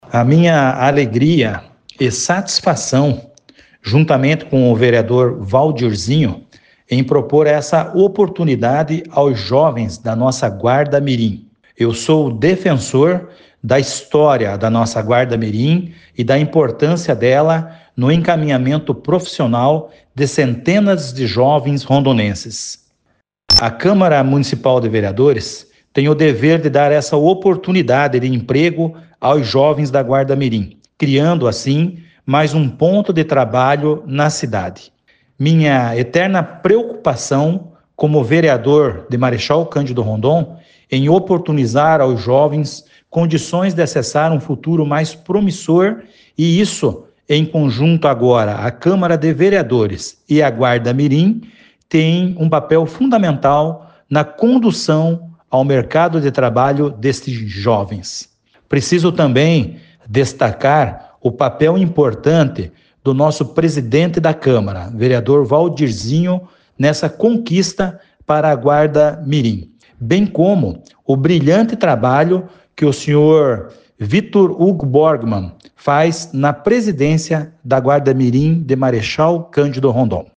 Ouça entrevista com o vereador coronel Welyngton..